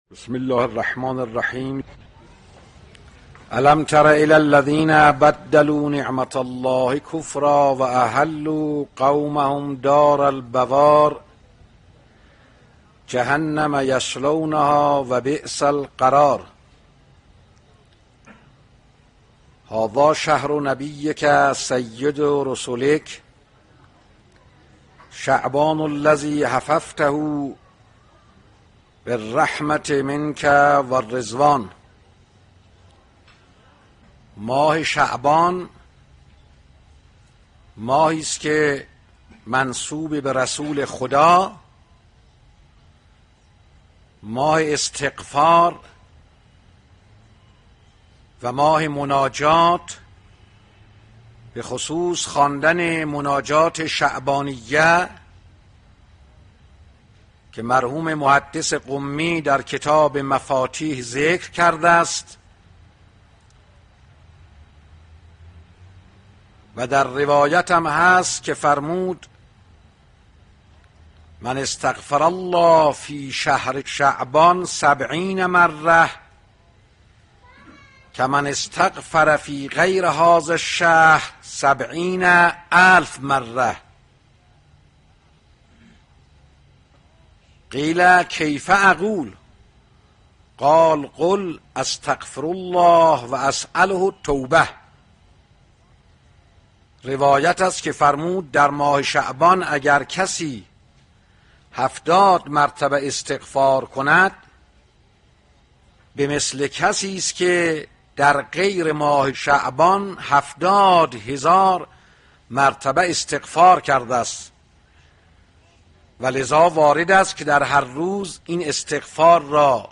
دانلود سخنرانی ولادت امام سجاد (علیه السلام)